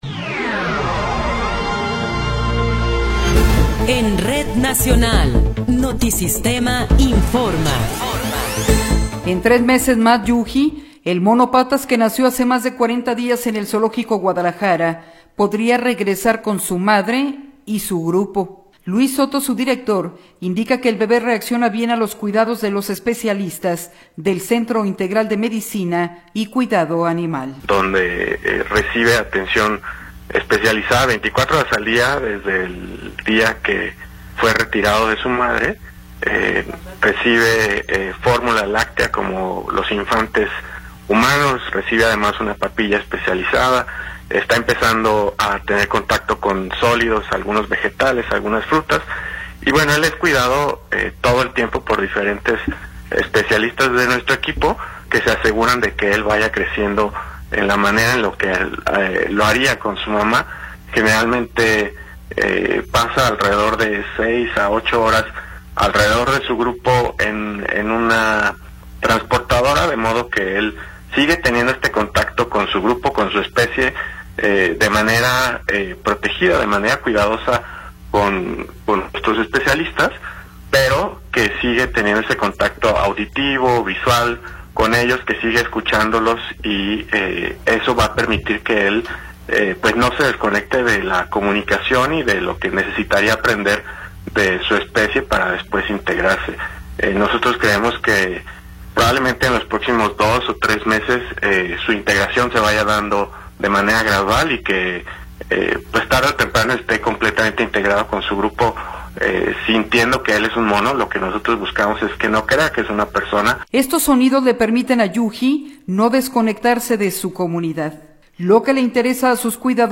Noticiero 15 hrs. – 19 de Abril de 2026
Resumen informativo Notisistema, la mejor y más completa información cada hora en la hora.